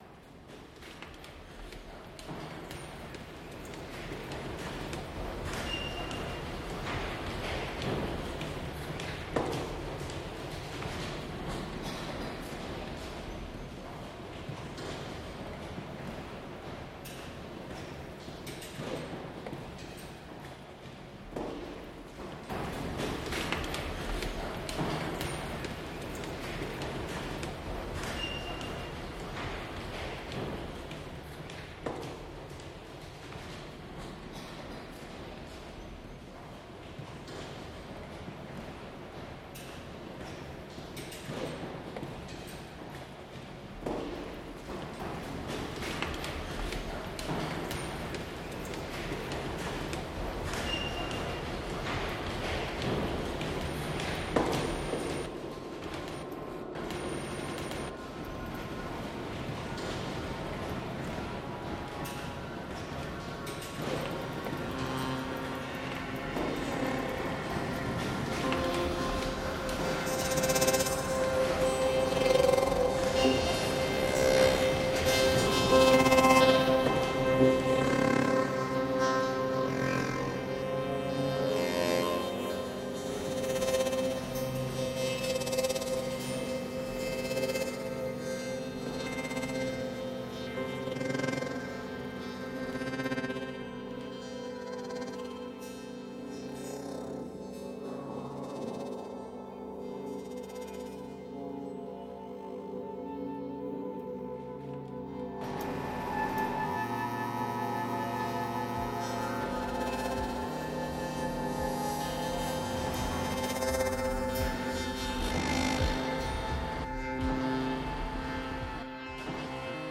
I created the piece in one take, using the original field recording throughout most of the writing process. Nearly 90% of the piece, including the main drones and glitchy percussive parts, was created using the original recording.